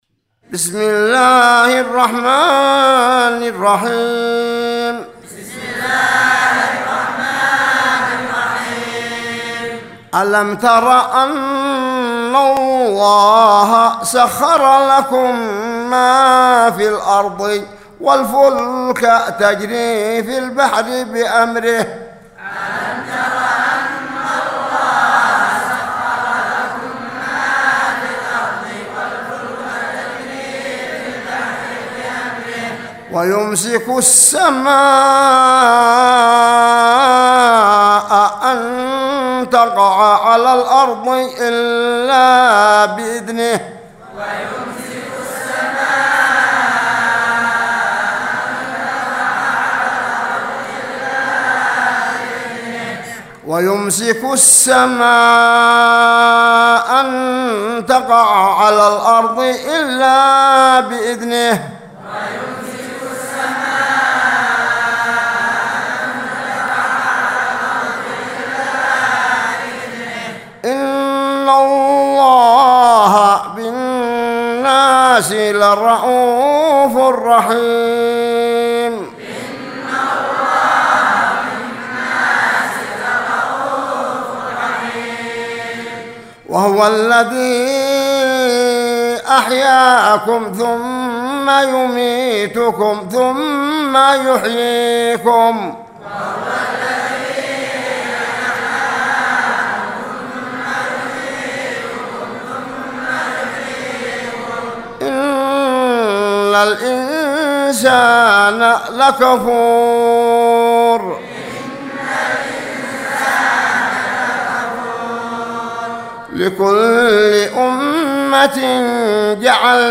سورة الحج مع الترديد من 65_72